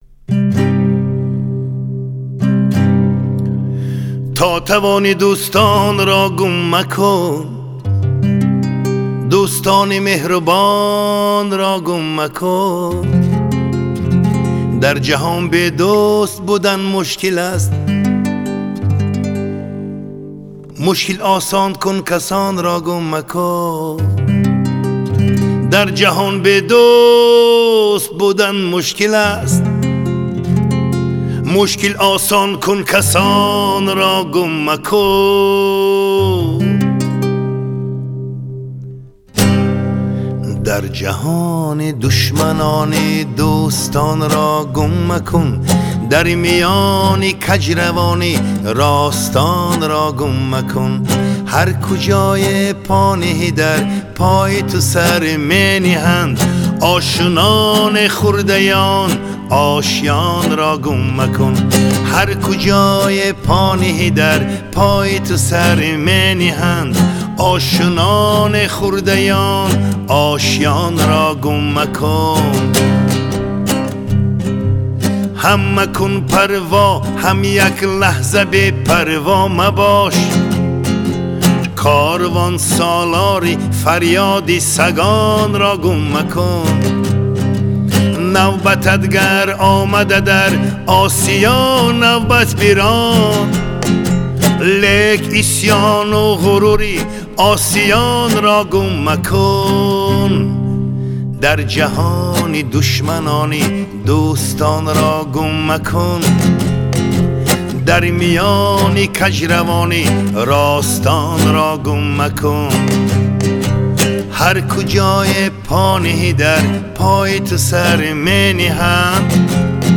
Суруди точики бо гитара mp3.